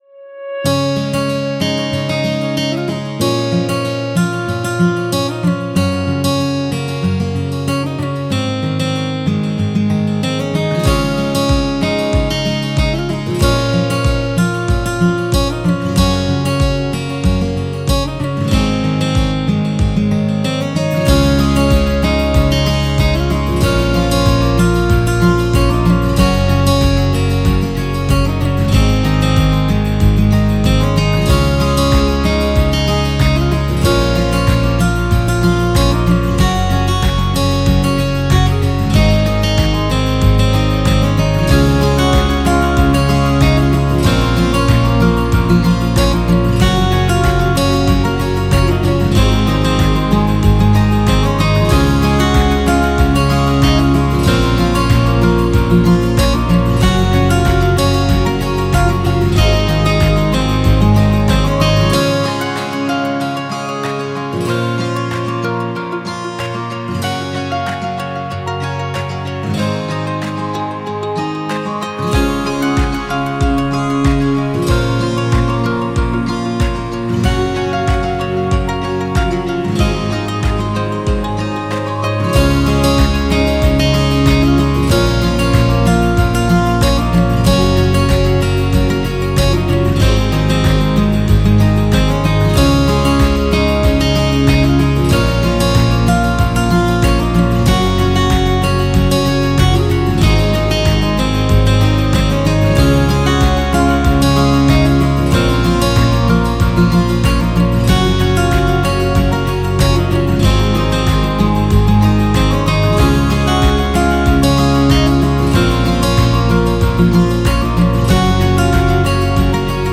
Genre: easylistening, folk.